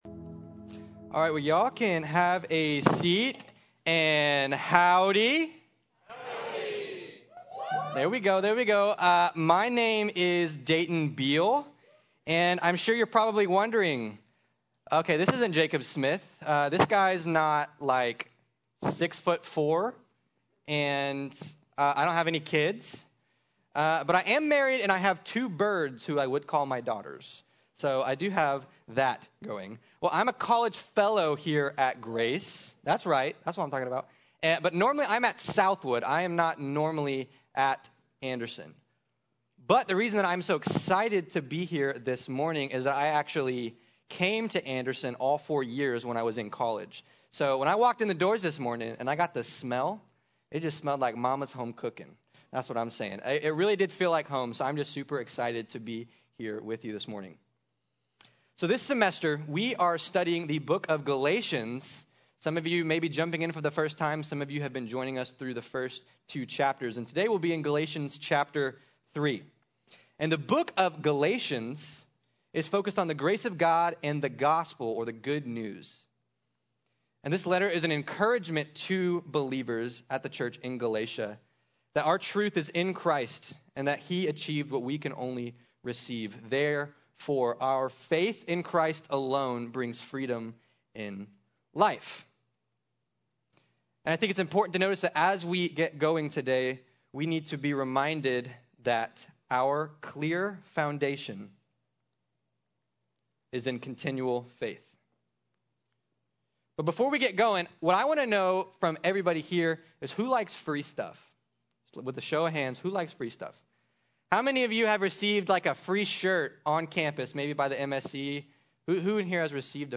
Life is Found in Christ Alone | Sermon | Grace Bible Church